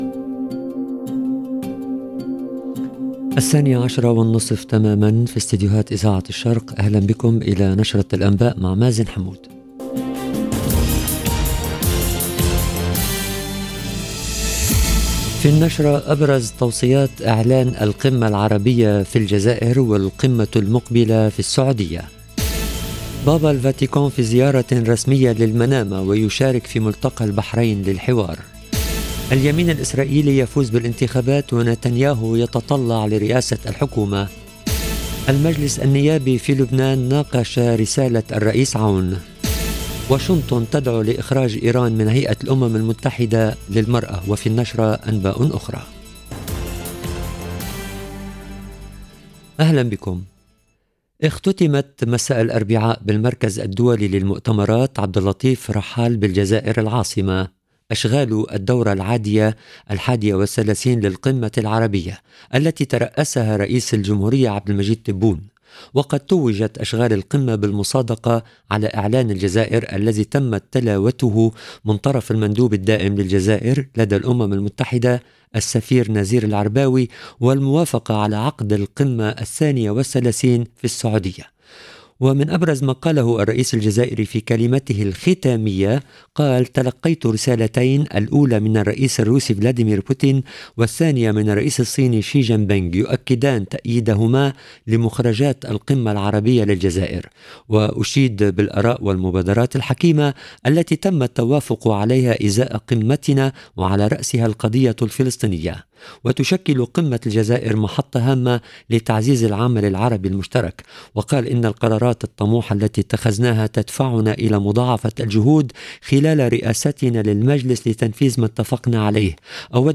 LE JOURNAL DE 12H30 EN LANGUE ARABE DU 3/11/2022